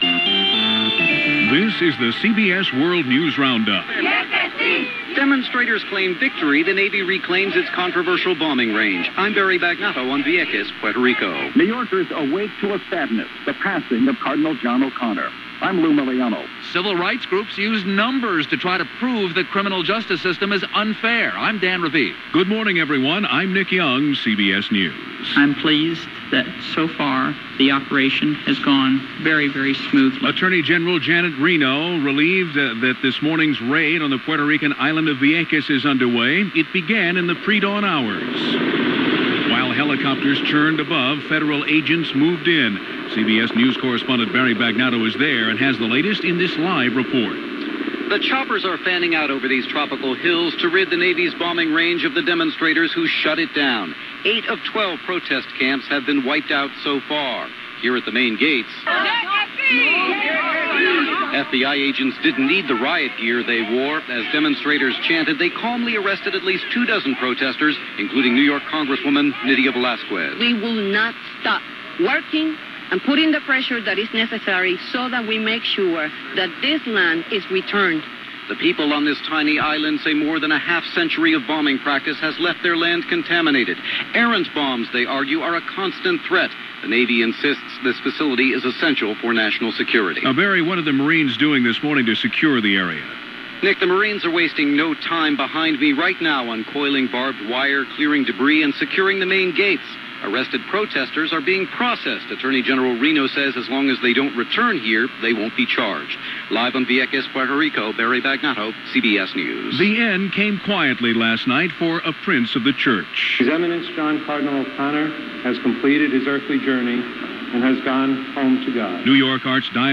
And that’s just a little of what went on, this May 4th in 2000 as reported by The CBS World News Roundup.